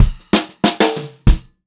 96POPFILL1-R.wav